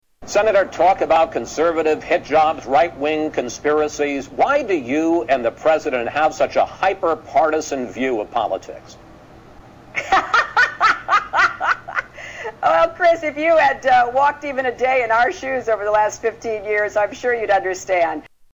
Hillary laughs at Chris Wallace